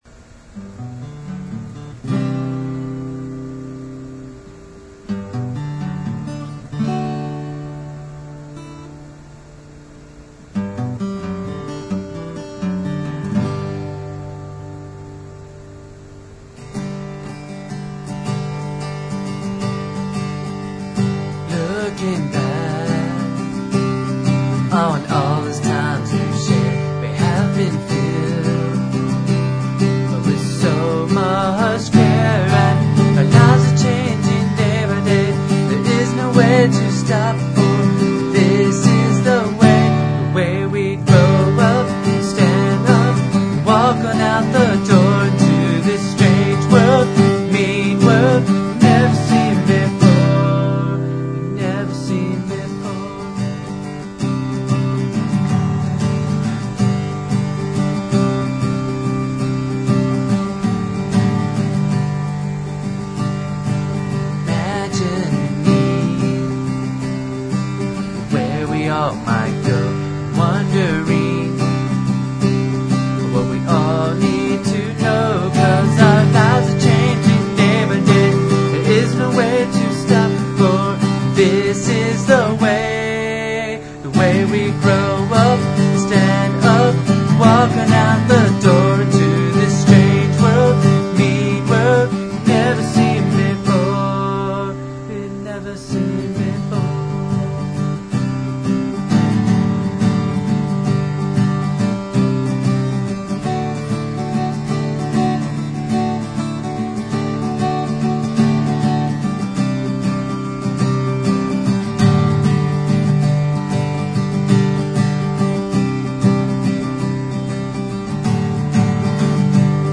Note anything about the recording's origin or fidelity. The songs on the site are rough cuts.